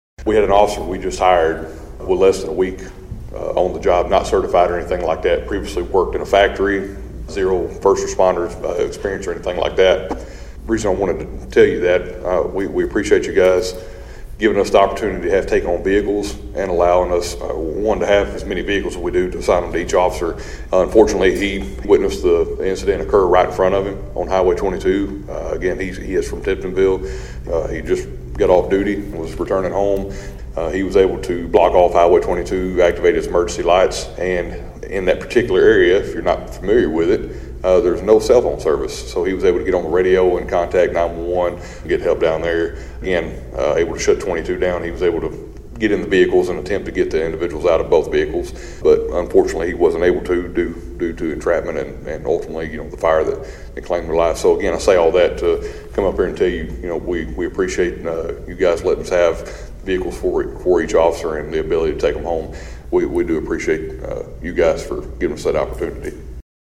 Union City Police Chief Ben Yates offered his appreciation to Council members at the latest monthly meeting.